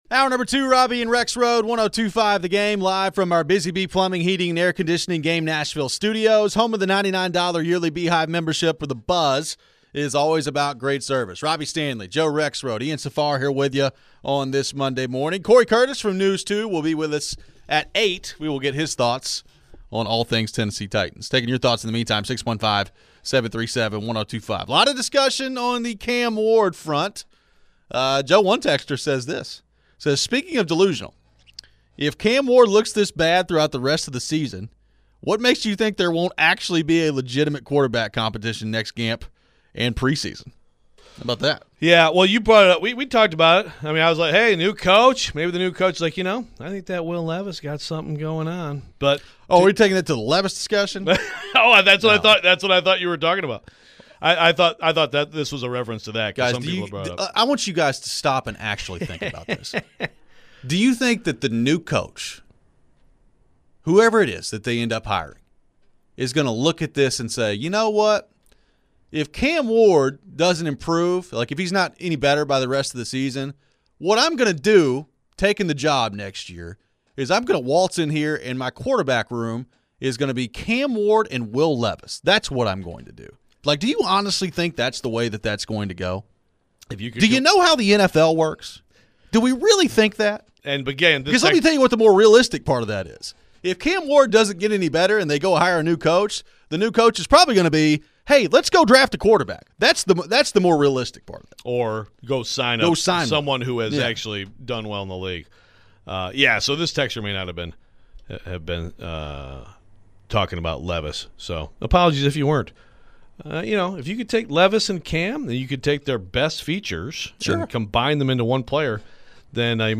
We continue the discussion on the Titans and Cam Ward. Did the offense look any better this week under interim HC Mike McCoy? We take your phones.